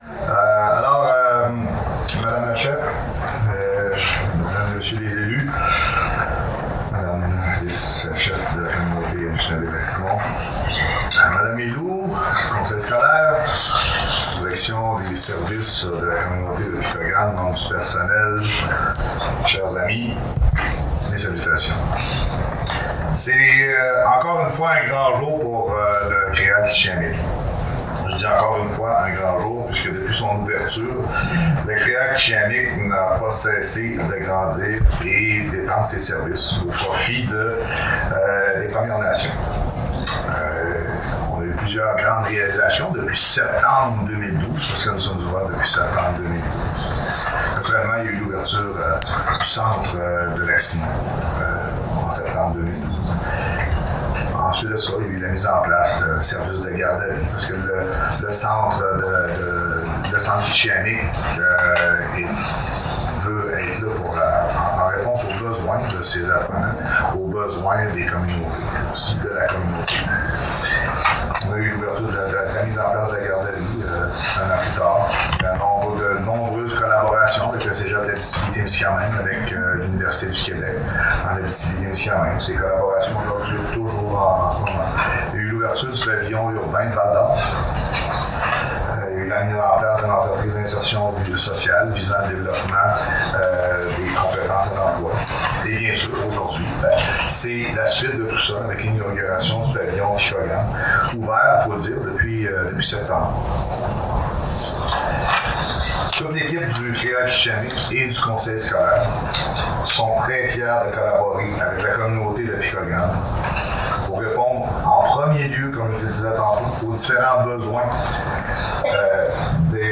livrent des discours, pendant l’inauguration d'un nouveau centre d'éducation régional pour les adultes (CRÉA) dans la communauté.